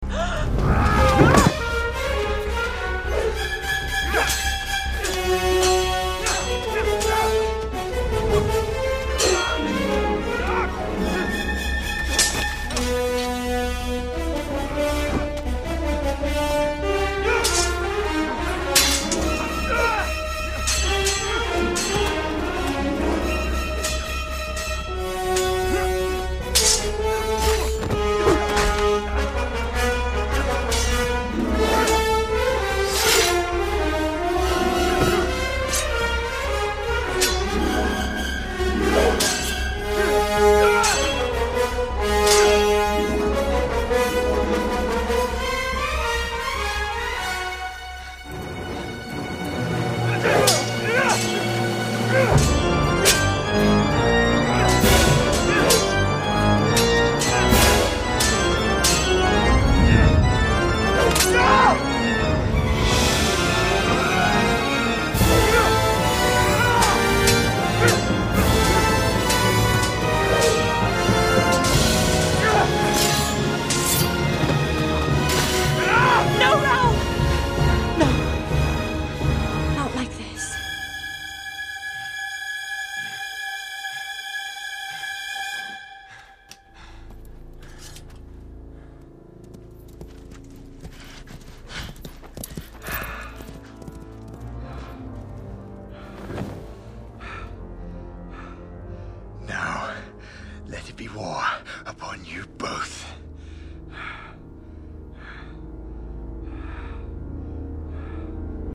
音乐类型：电影配乐